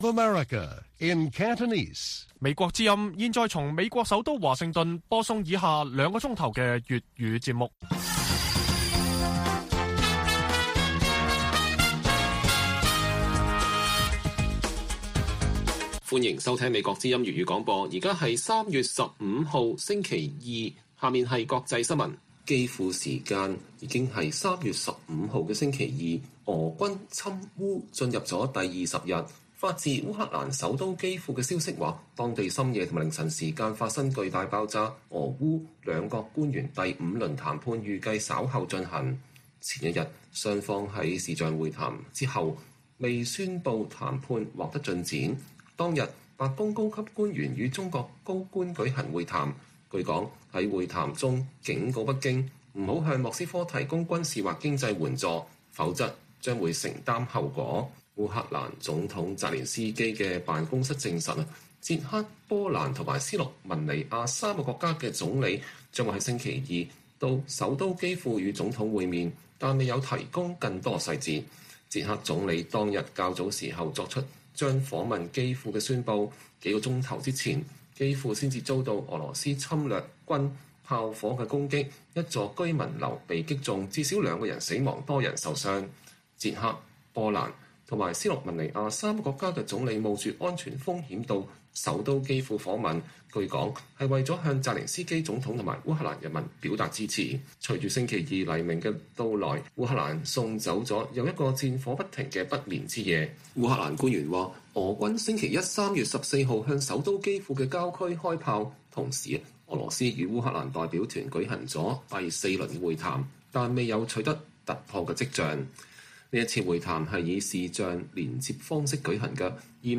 粵語新聞 晚上9-10點：基輔再被炸，俄烏談判稍後繼續